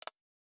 Simple Cute Alert 16.wav